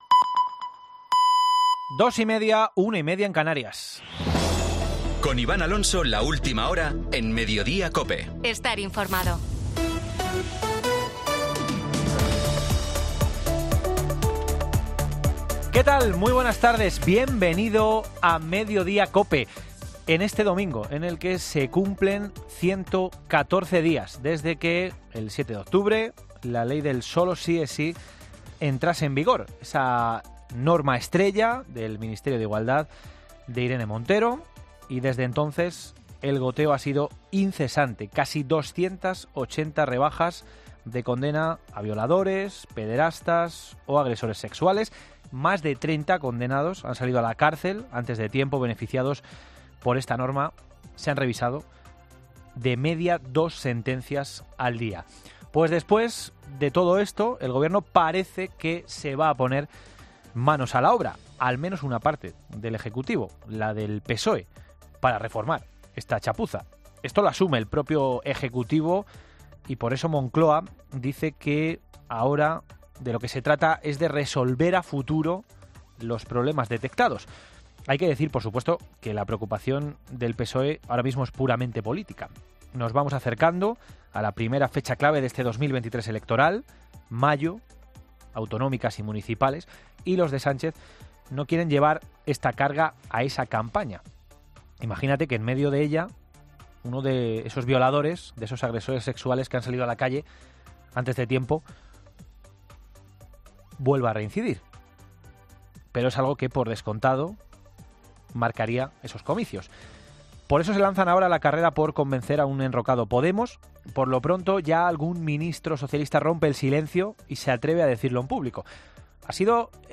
En COPE hablamos con un Catedrático en Derecho Penal y abogado penalista que nos da las claves de los cambios que pueden producirse en los reos con una posible reforma